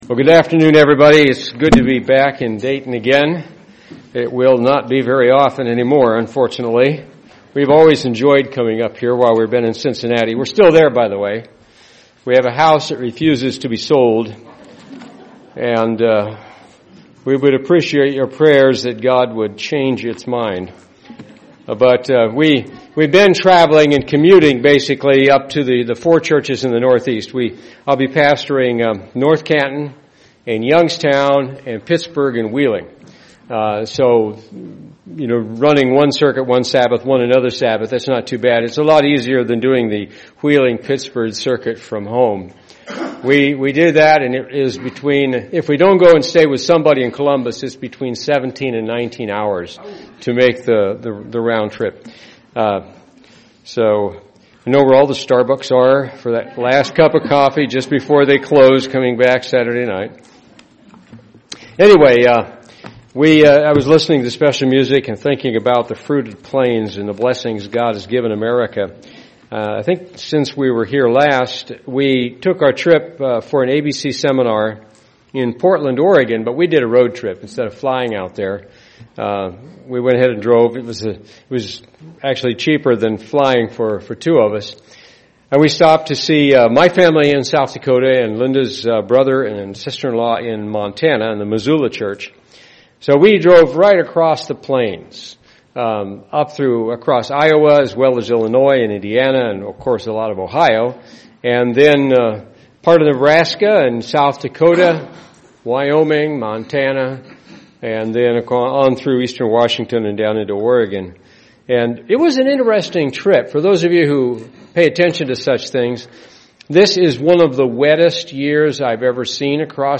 Print A look into the Laodecian and Philadelphian mindset UCG Sermon Studying the bible?